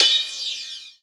D2 RIDE-05-L.wav